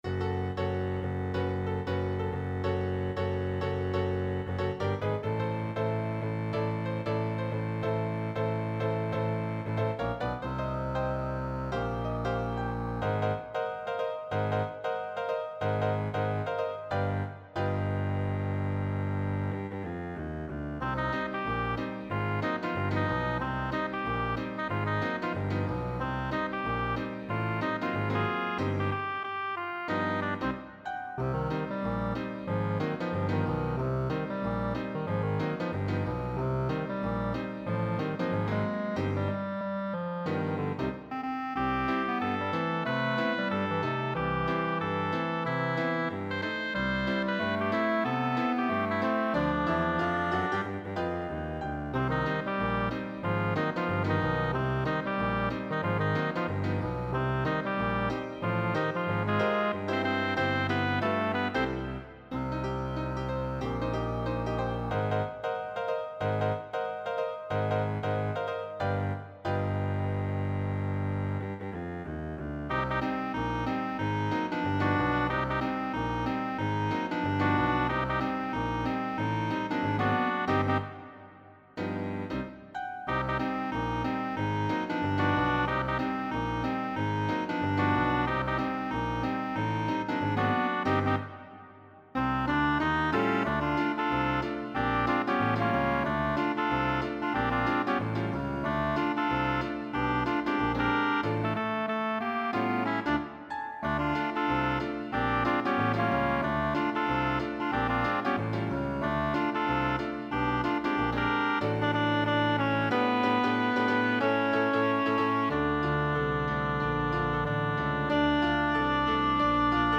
Voicing SATB Instrumental combo Genre Swing/Jazz